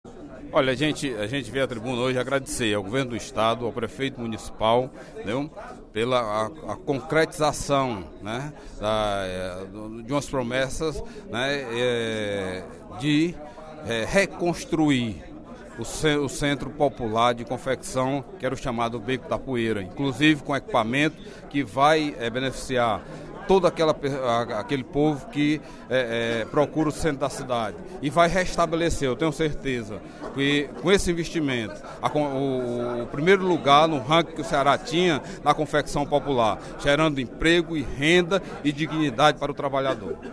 O deputado Delegado Cavalcante (PDT) fez pronunciamento, nesta sexta-feira (16/05), durante o primeiro expediente da Assembleia Legislativa, para agradecer ao prefeito de Fortaleza, Roberto Cláudio, pela reestruturação do chamado “Beco da Poeira” e do “Esqueleto”, que, segundo ele, terão uma das maiores estações do metrô de Fortaleza.